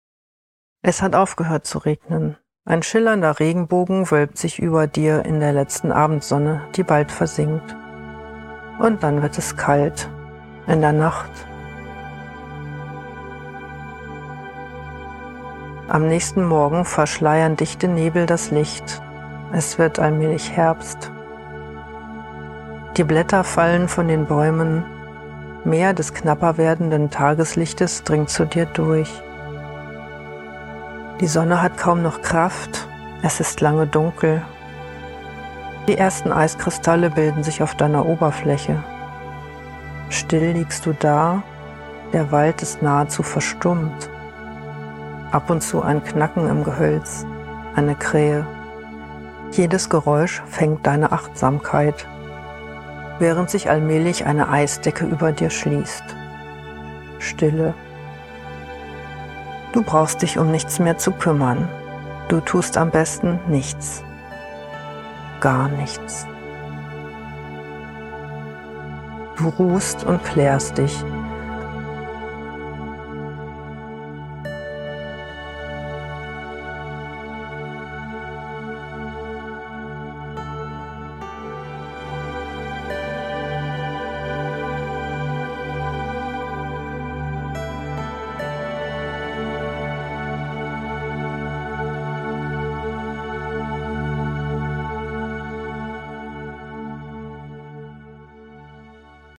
Waldmeditation: „Sonntagsmeditation“ zum Kraftschöpfen für die Woche